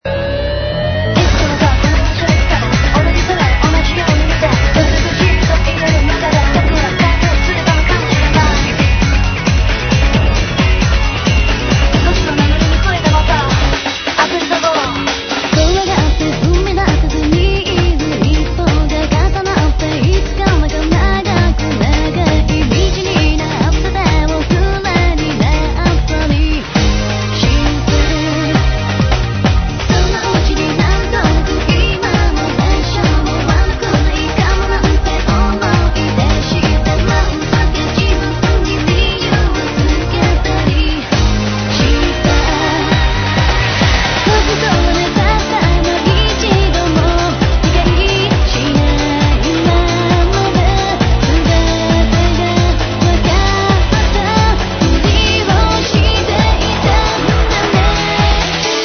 Genre : Techno/Dance/Electro-Pop